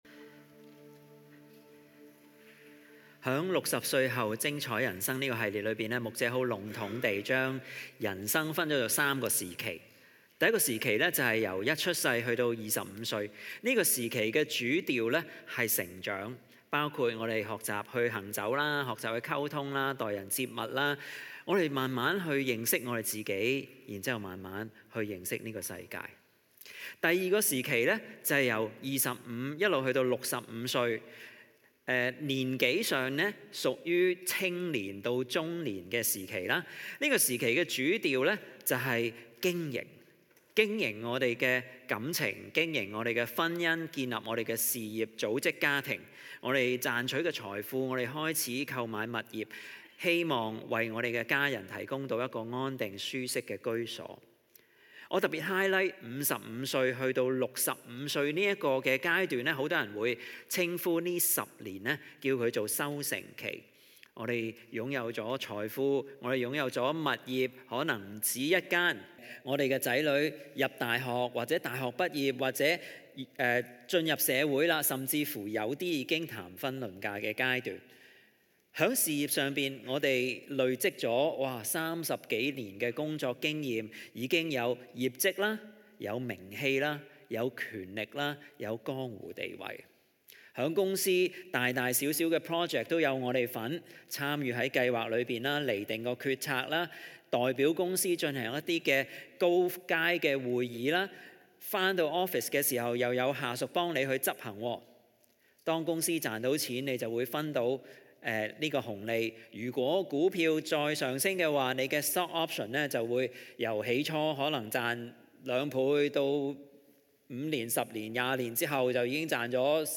在這個講道系列「Encore」的第三堂，講員帶領我們面對人生 65 歲後的「逆轉期」，並透過大衛年老時所寫的詩篇 71 篇，找出三個讓晚年活得精彩、充滿生命力的「逆齡秘方」。